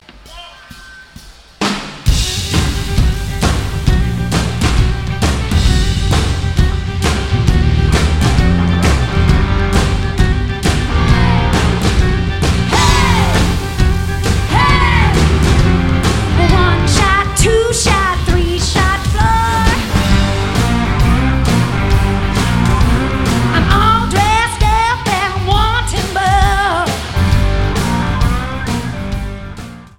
Blues
Country
Funk
Rock